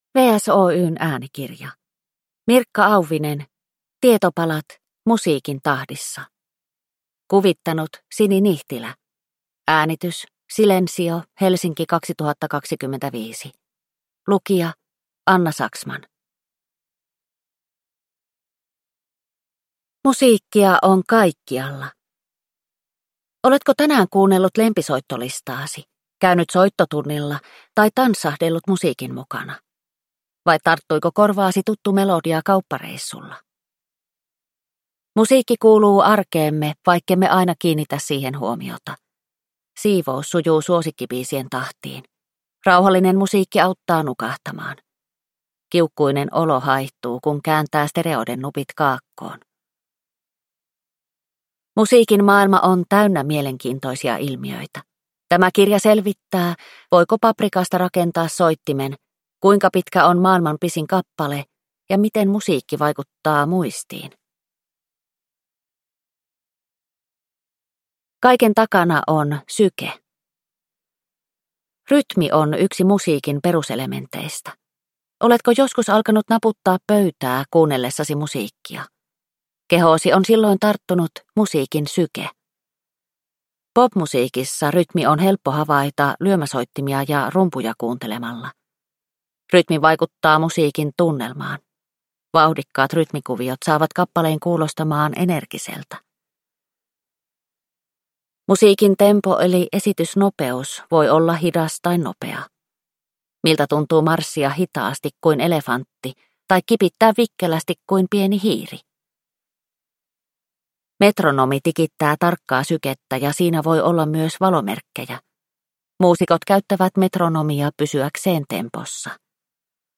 Tietopalat: Musiikin tahdissa – Ljudbok